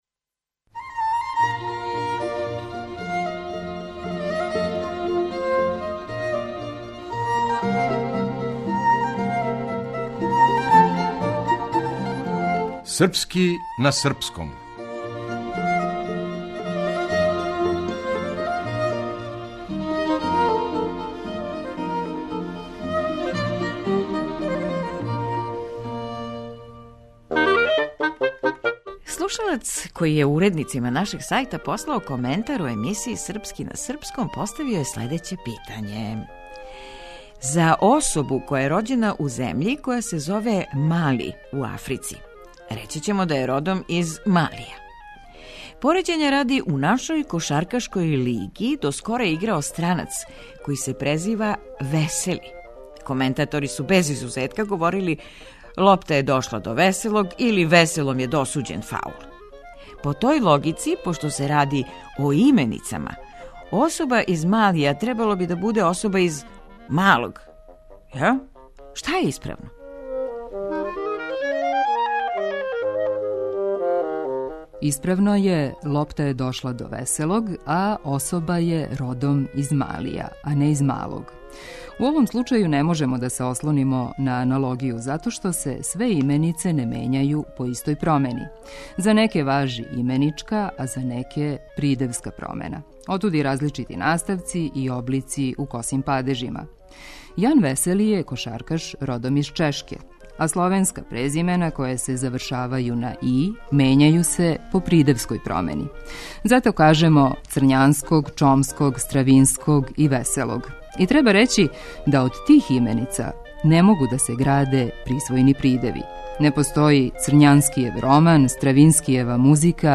Драмски уметник: